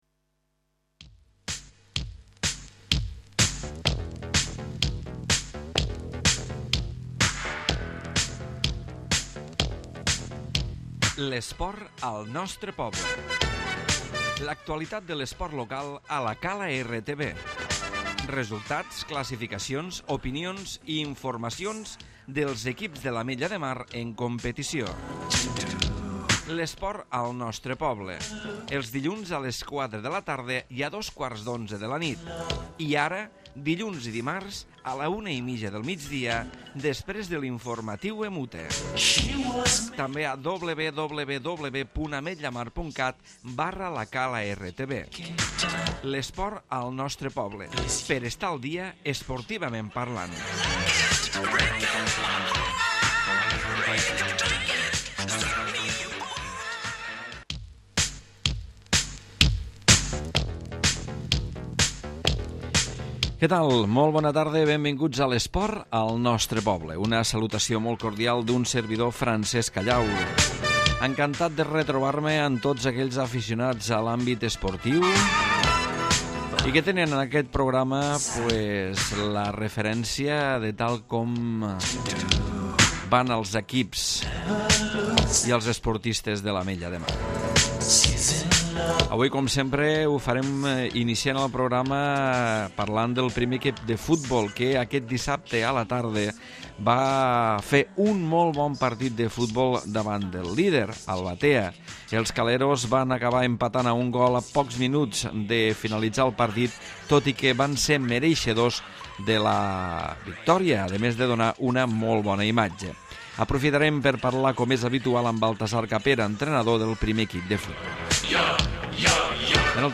Entrevista amb l'entrenador del Juvenil